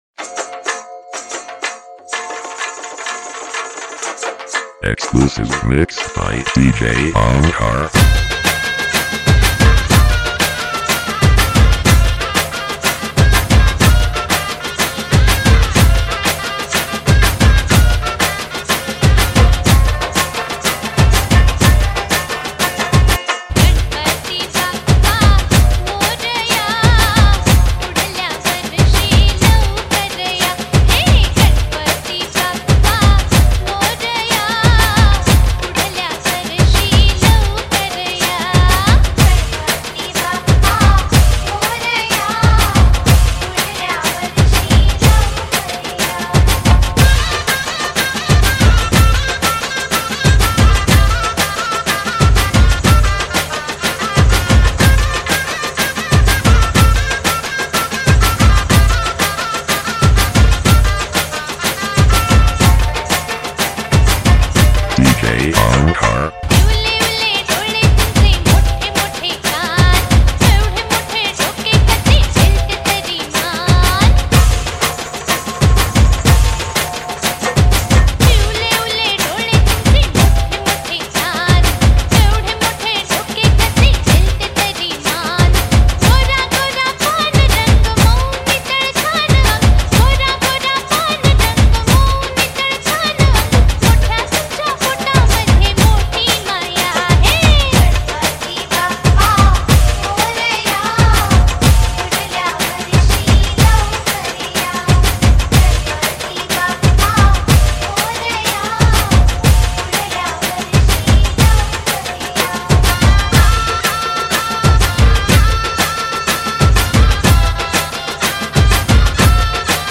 Ganesh Chaturthi Ganpati DJ Remix Songs